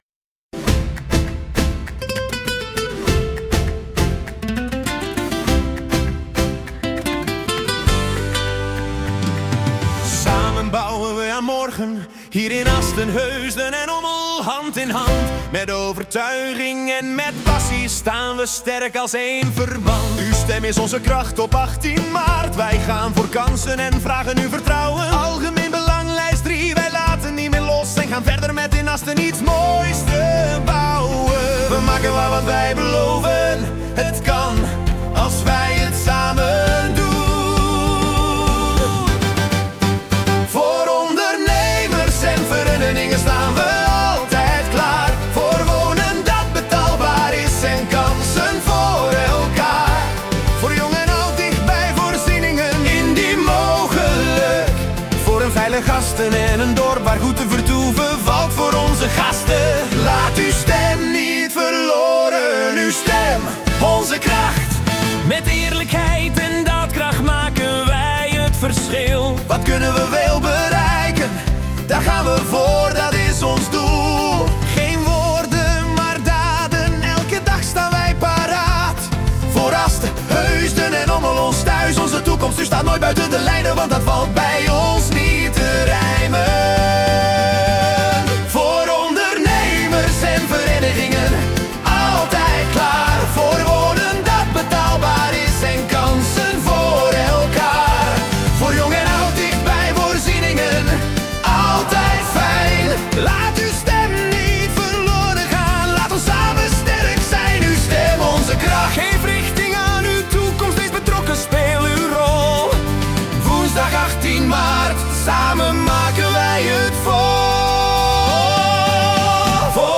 Campagnespotjes Algemeen Belang